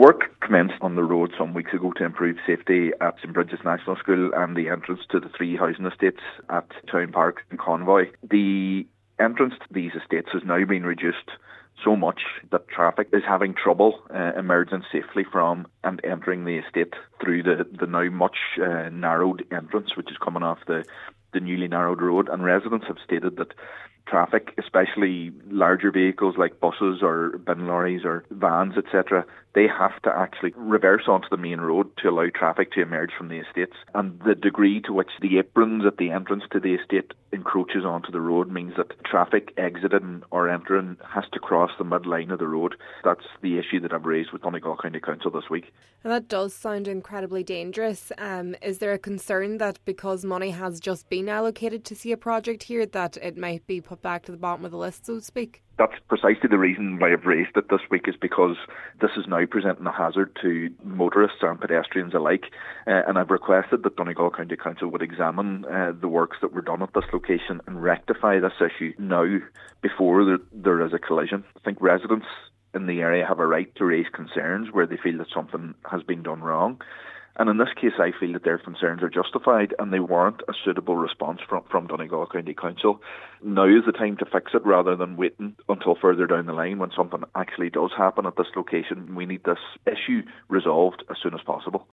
Councillor Gary Doherty fears the way traffic is being forced to manoeuvre will result in a crash unless action is taken: